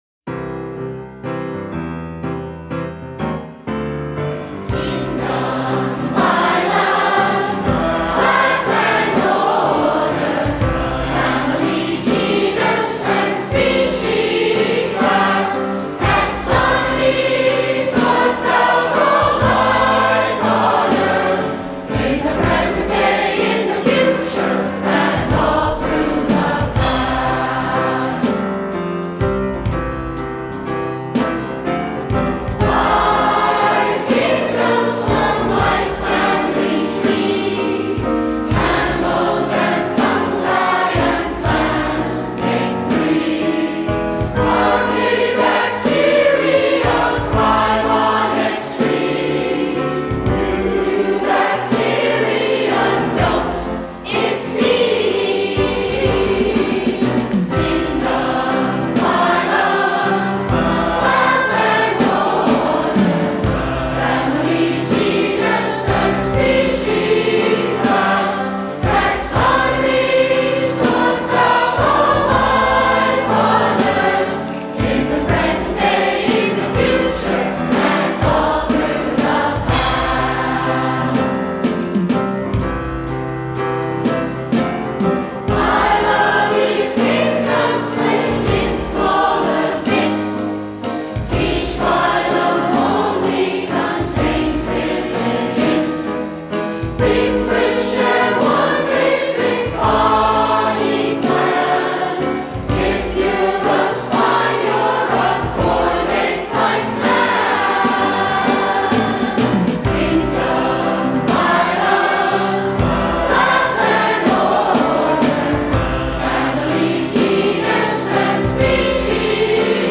Below you can hear the 2007 Festival Chorus performing Lifetime: Songs of Life and Evolution.